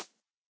sounds / mob / rabbit / hop3.ogg
hop3.ogg